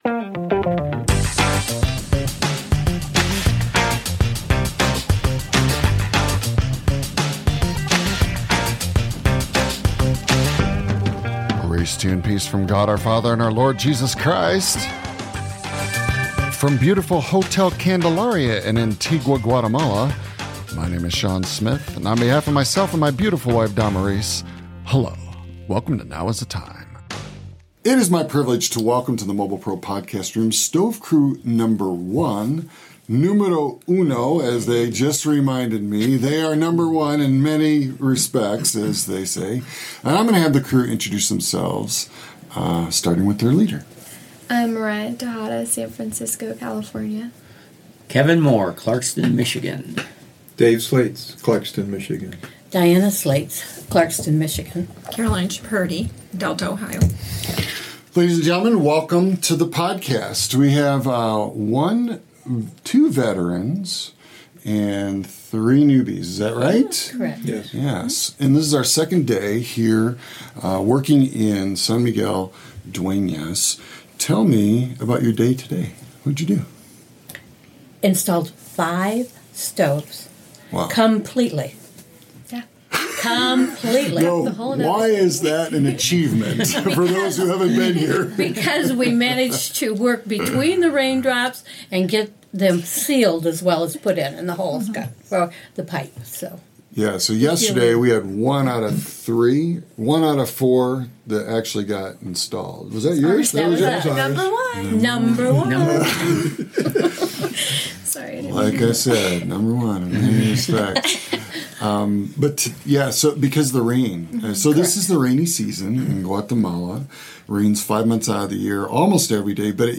This podcast was created and published completely within an iOS device (iPhone, iPad, or iPod Touch) - no computer, no external batteries, or external power.